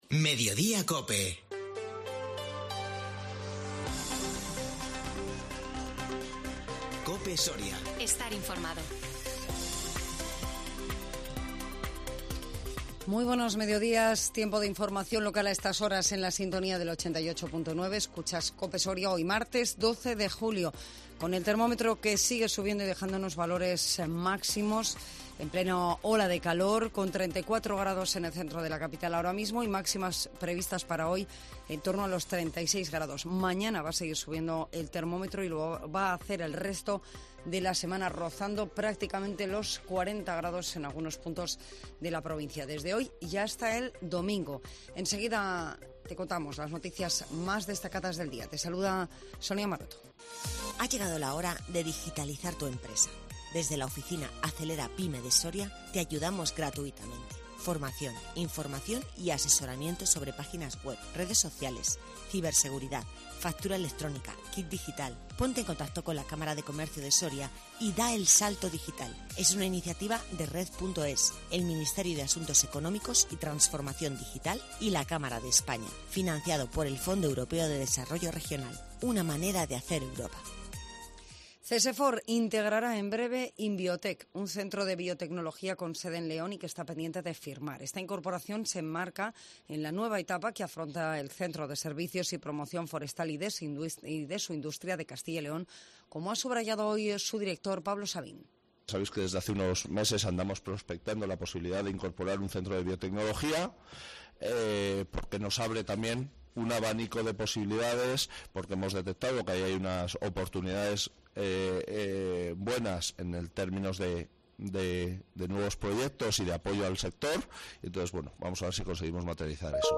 INFORMATIVO MEDIODÍA COPE SORIA 12 JULIO 2022